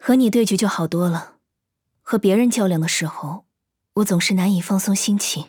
【模型】GPT-SoVITS模型编号130_女-secs